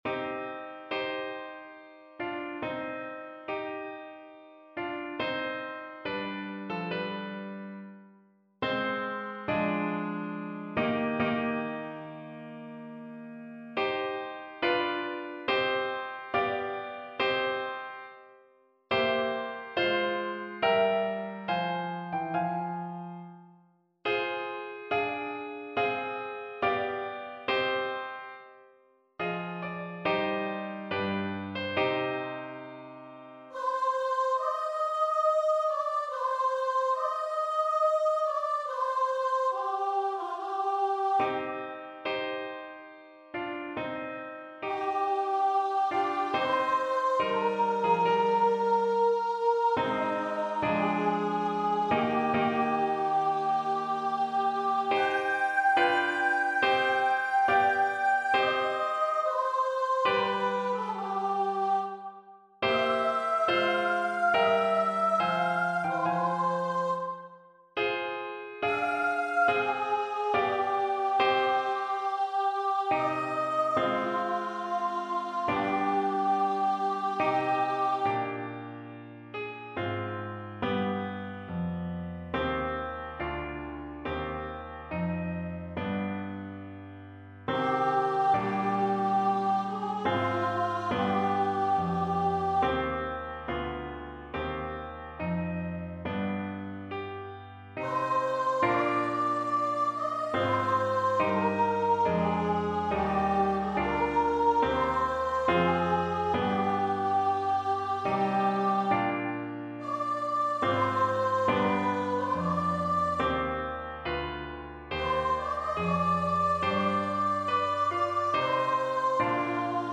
Free Sheet music for Choir
Choir  (View more Intermediate Choir Music)
Classical (View more Classical Choir Music)